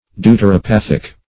Meaning of deuteropathic. deuteropathic synonyms, pronunciation, spelling and more from Free Dictionary.
Search Result for " deuteropathic" : The Collaborative International Dictionary of English v.0.48: Deuteropathic \Deu`ter*o*path"ic\, a. Pertaining to deuteropathy; of the nature of deuteropathy.